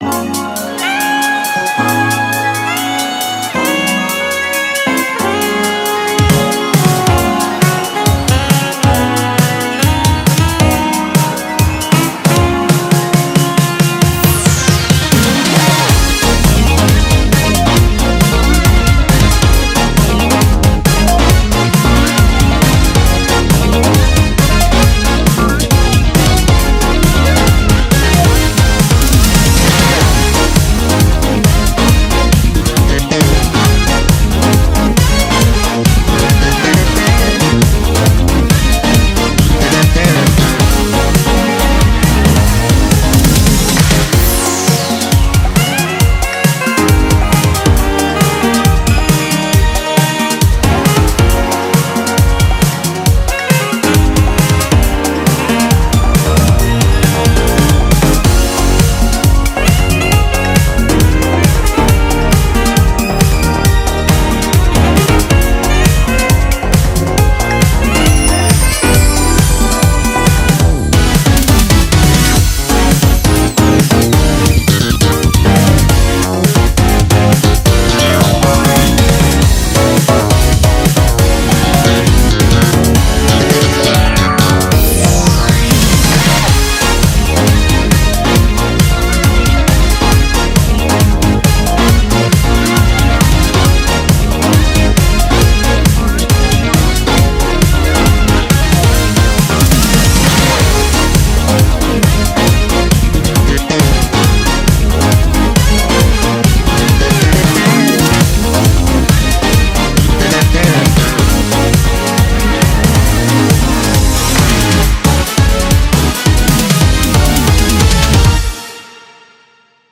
BPM136
Audio QualityPerfect (High Quality)
Comments[80's SYNTH FUNK]